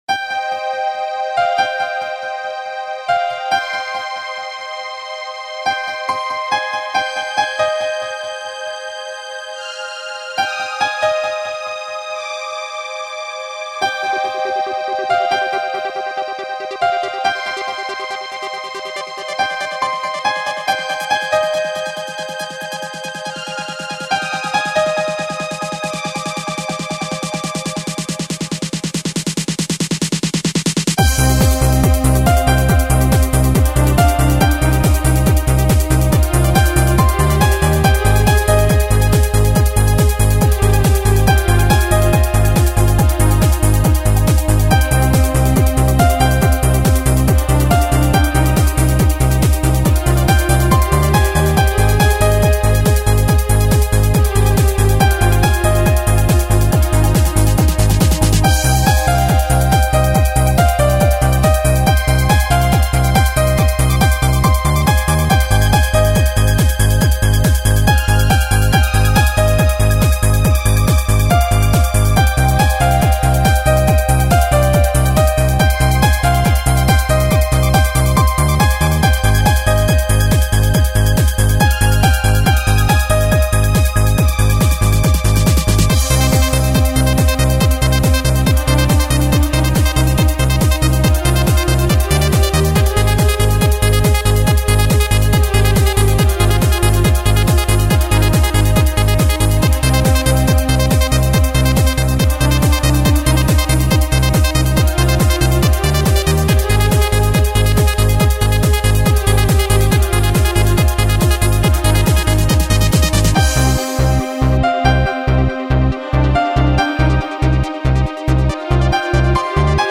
Энергия - эмоции - чувства - движение...
Жанр:Electronic